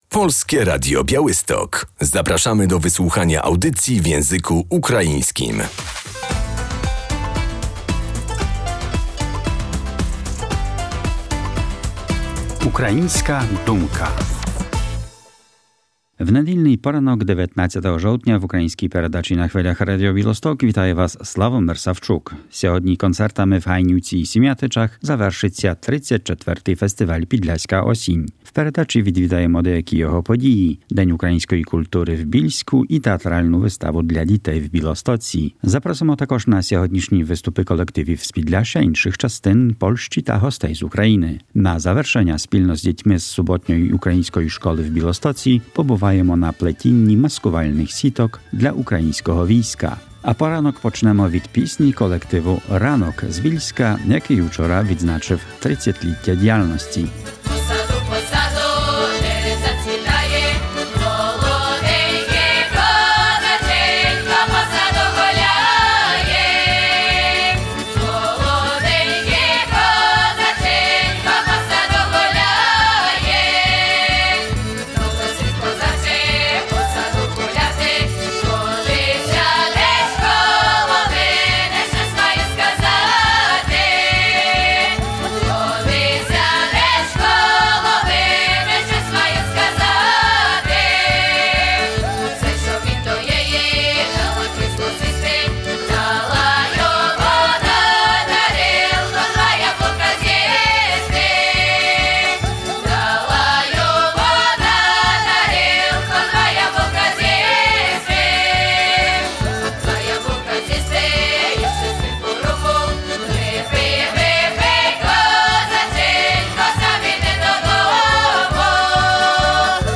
Koncertami w Hajnówce i Siemiatyczach kończy się dziś 34. Festiwal Podlaska Jesień. W audycji będziemy na jednym z jego wydarzeń – Dniu Kultury Ukraińskiej w Bielsku Podlaskim